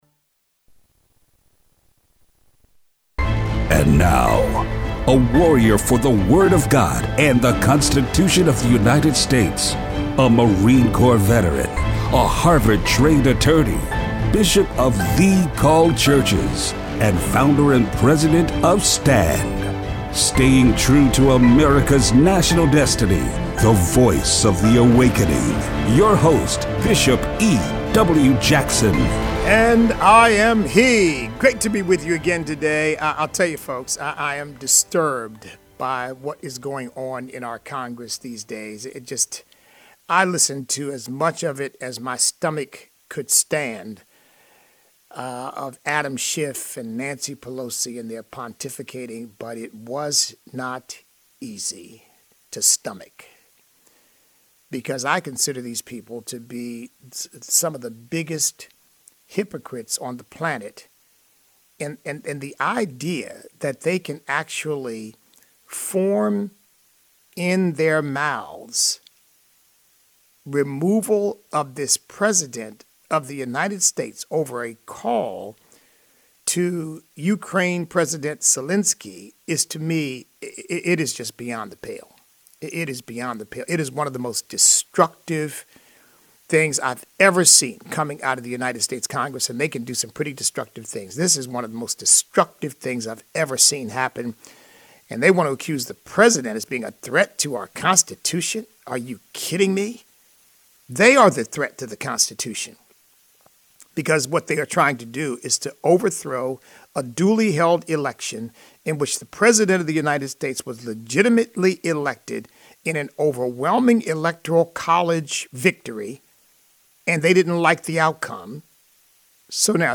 Show Notes Governor Ralph Northam declared a state of emergency in advance of expected demonstrations on Capitol Square on Monday, January 20, 2020. Listener call-in.